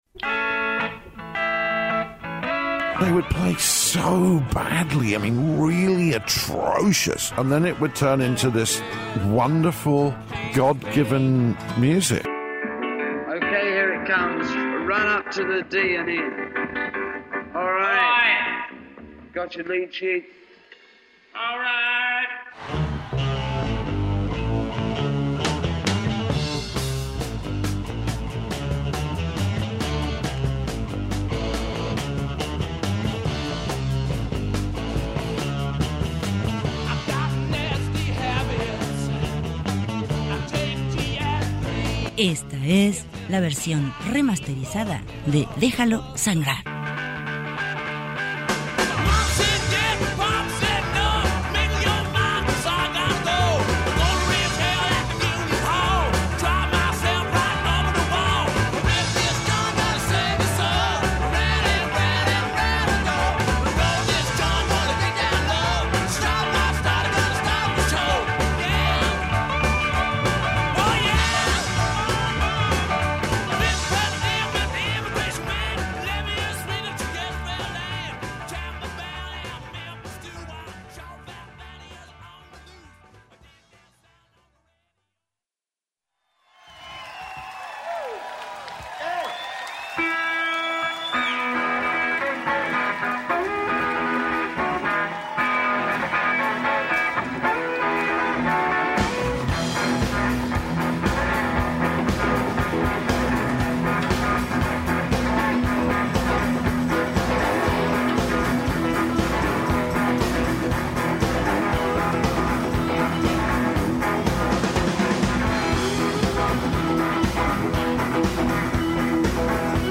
música negra